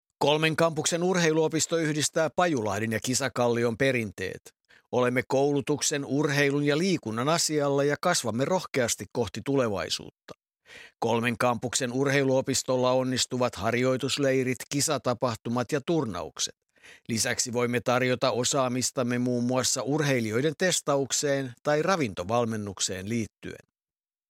Kolmen kampuksen urheiluopisto -äänimainos - Materiaalipankki
Kolmen_kampuksen_urheiluopisto_aanimainos.mp3